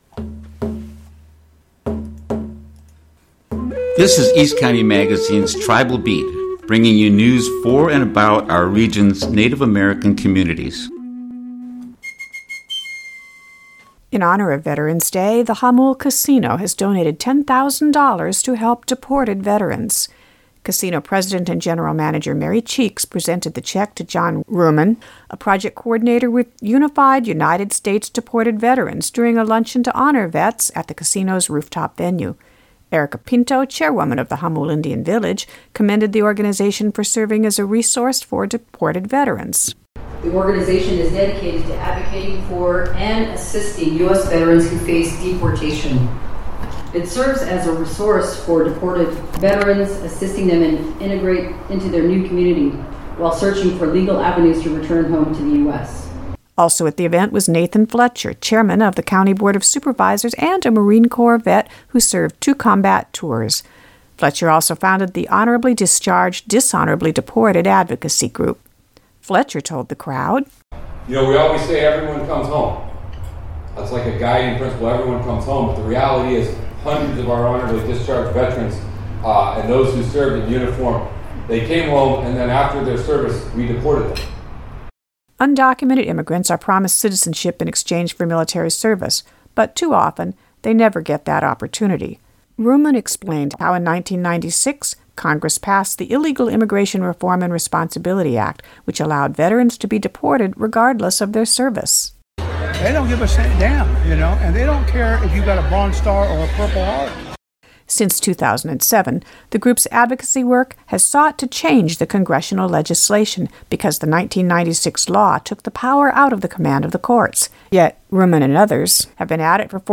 Hear an audio version of this report which aired on the East County Magazine Radio Show on KNSJ, including excerpts of speakers, by clicking the audio link.